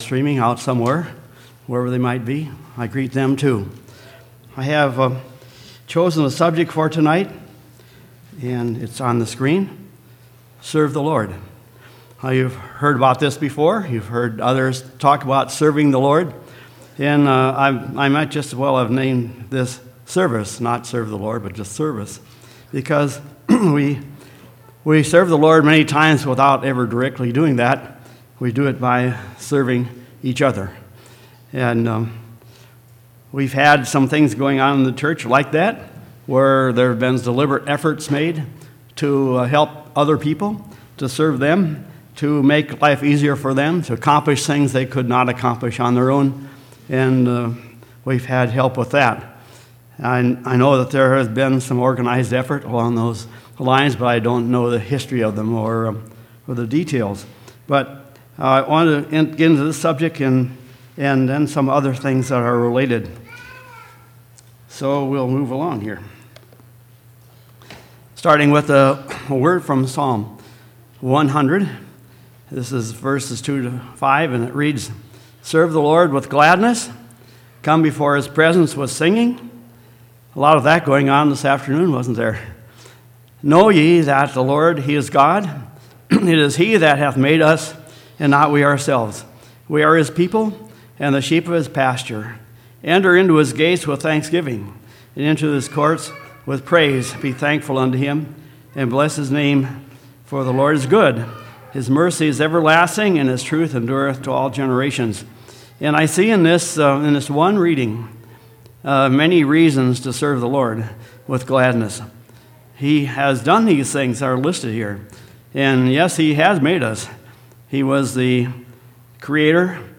12/3/2017 Location: Temple Lot Local Event